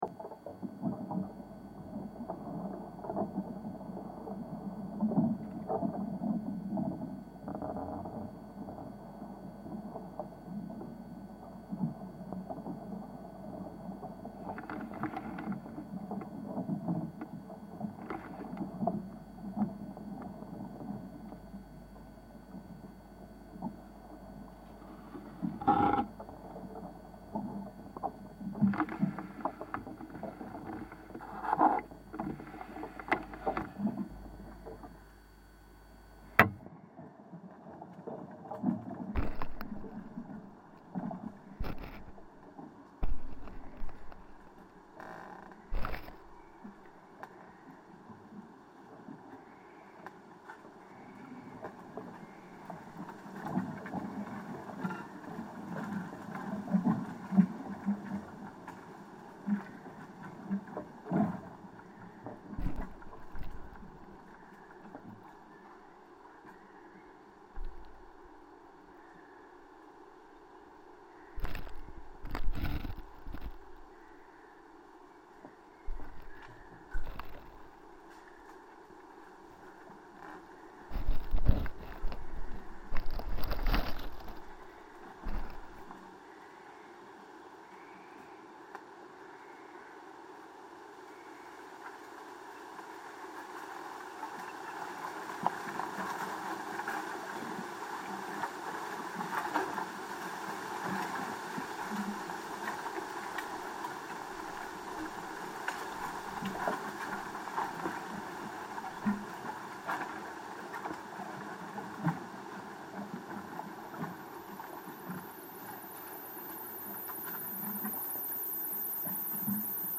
I therefore recorded the vibrations and crackling of the structure using geophones.
I will also use analogue synthesis, as this is my priority at the moment. And I’ll have to take into account the noise made by the frogs in the main pond between 7 and 8 p.m. The volume is unbelievable, and the way their croaking echoes in the distance is already unforgettable.